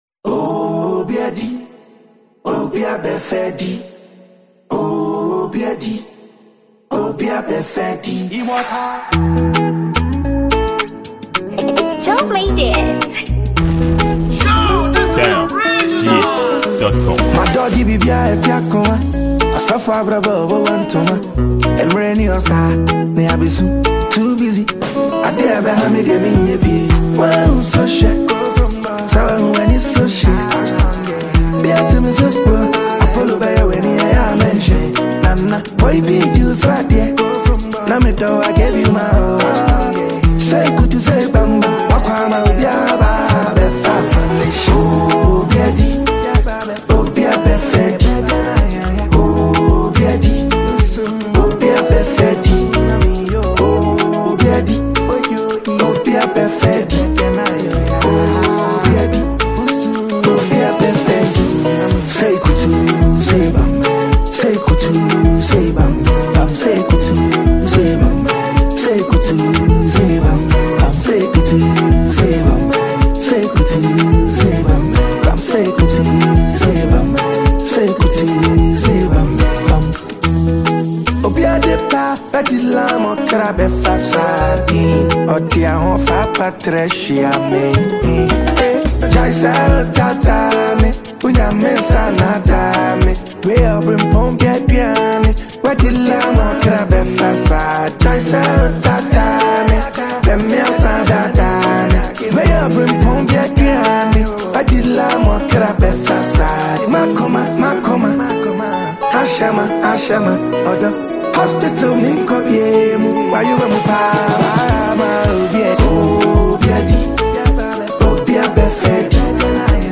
a Ghanaian rapper, singer and songwriter